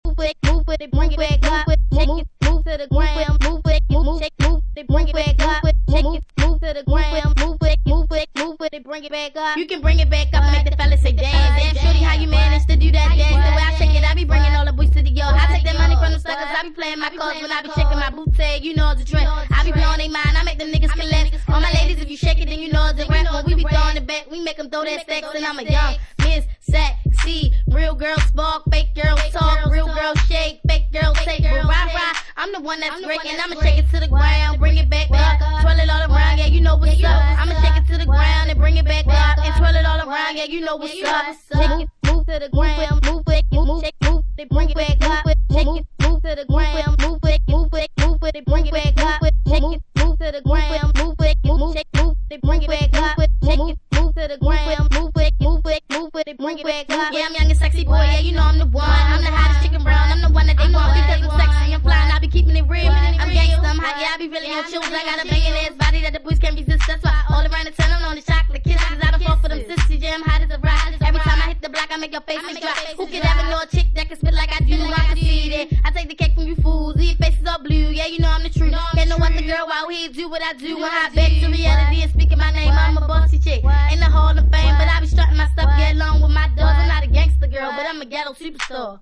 Electro Hip Hop